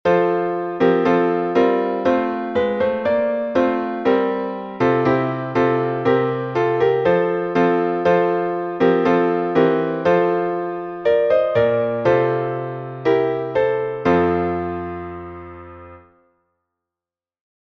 сербский напев